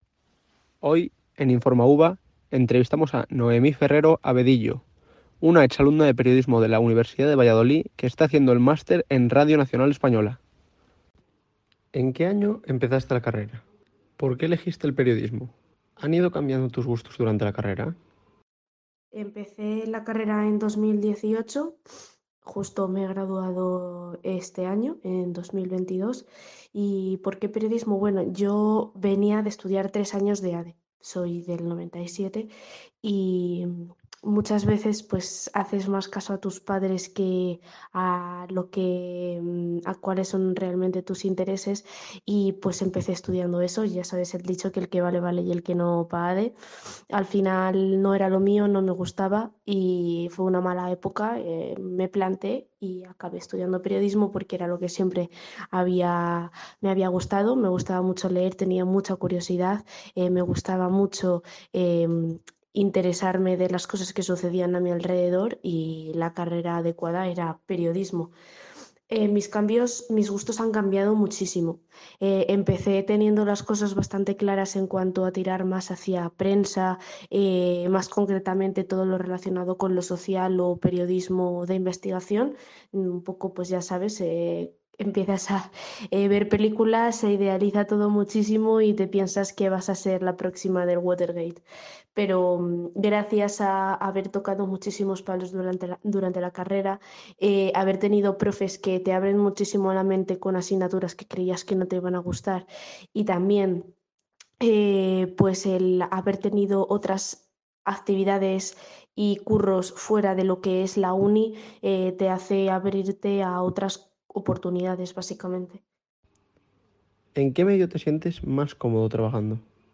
En esta breve entrevista conoceremos un poco más de cerca la experiencia de esta antigua compañera durante la carrera y el mundo que está descubriendo gracias a su elección de máster.